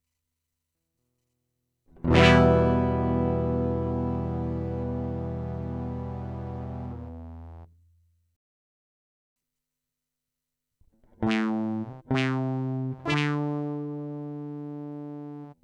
Using the SY-1000 OSC Synth. Regular guitar output pickup is panned left. SY-1000 output of OSC SYNTH is panned right. Here you can hear the delay.
SY1000_Osc_Synth.flac